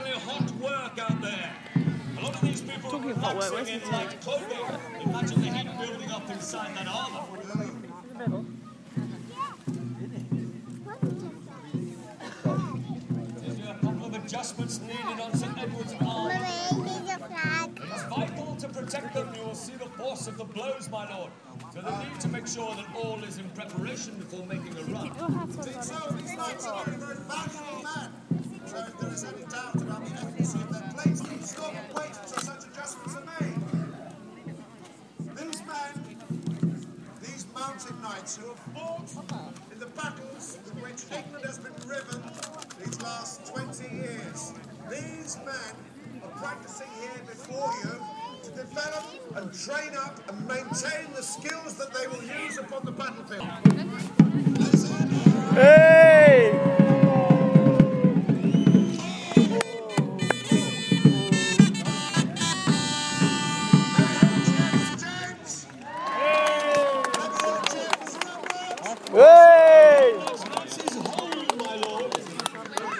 Jousting at Bolsover Castle